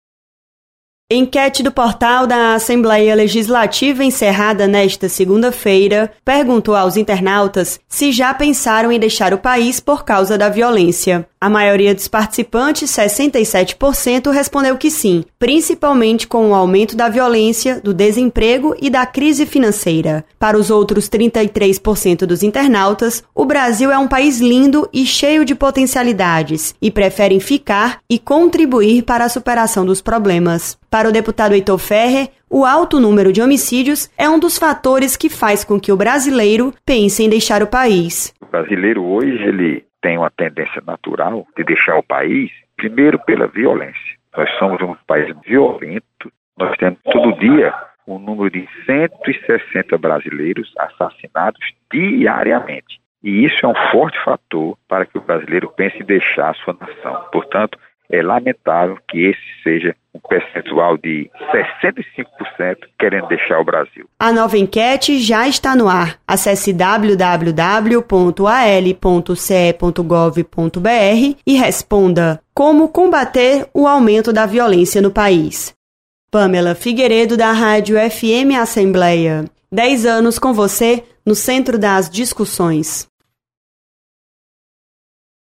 Enquete